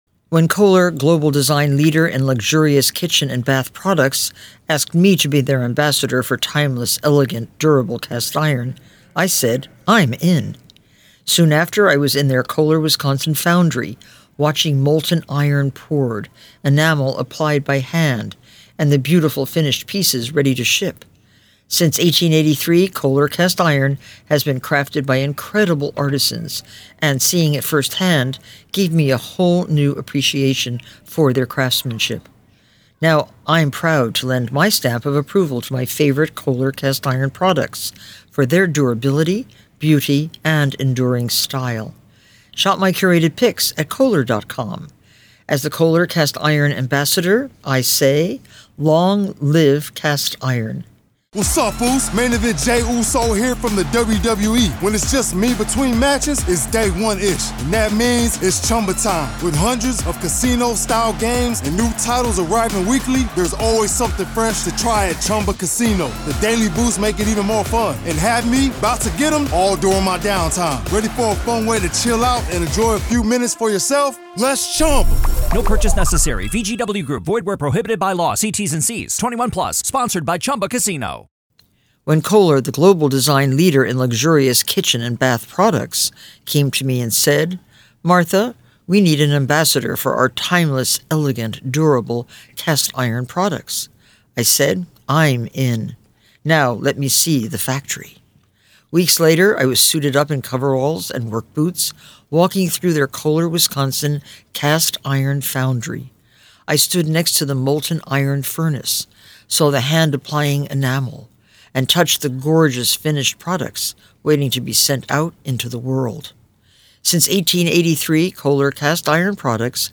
The conversation took an interesting turn as they discussed the elusive alibi the defense is purportedly withholding.